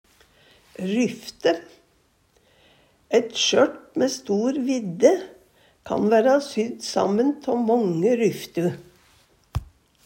ryfte - Numedalsmål (en-US)